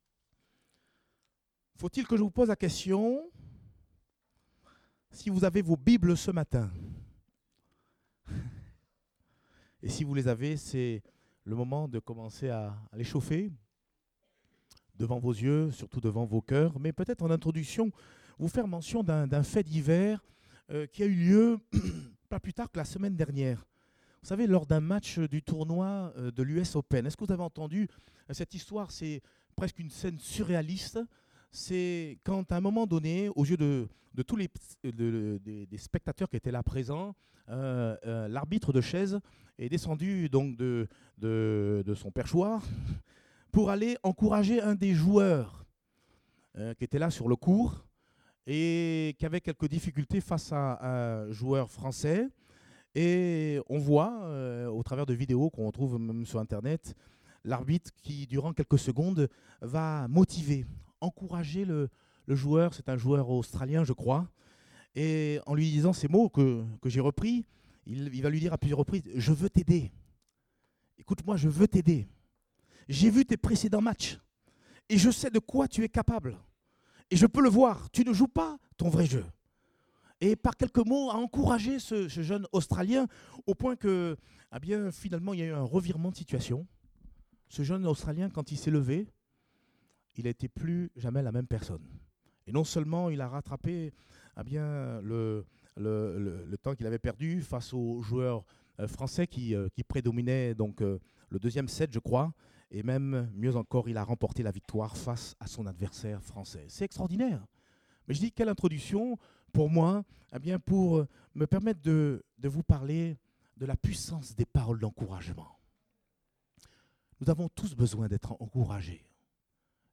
Date : 2 septembre 2018 (Culte Dominical)